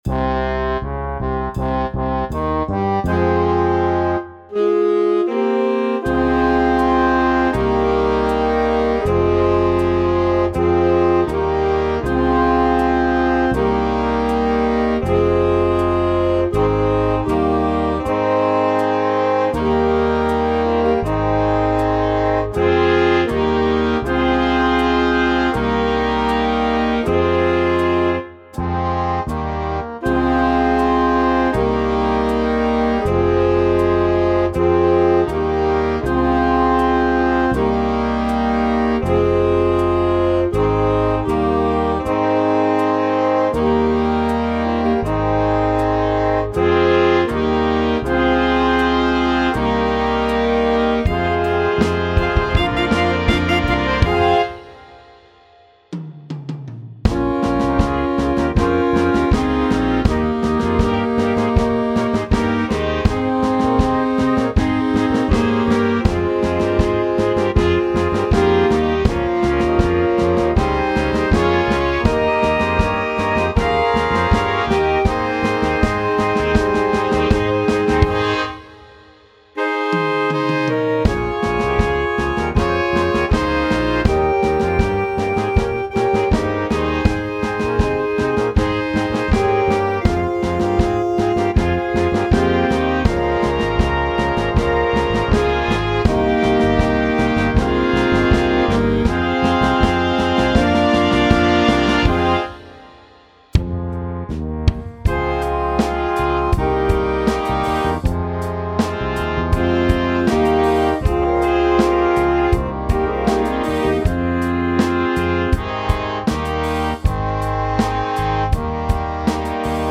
Dychová hudba Značky